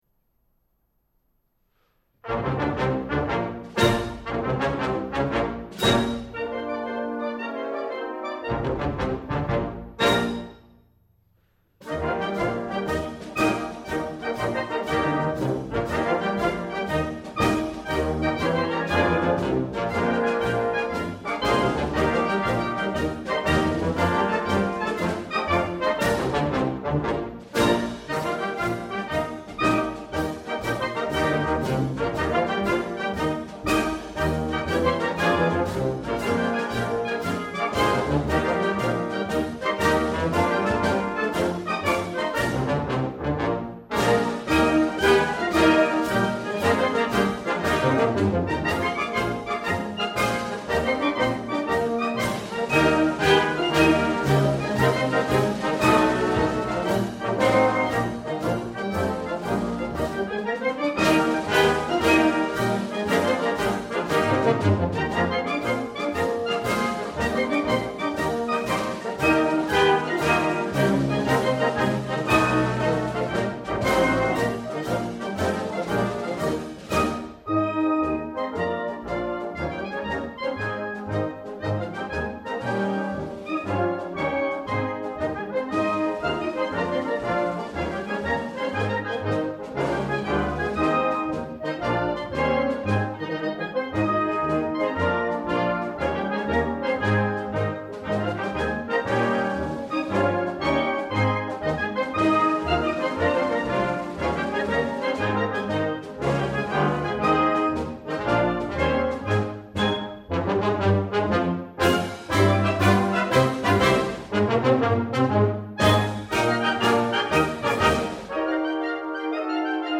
These were sight read and recorded on August 7'th 2007.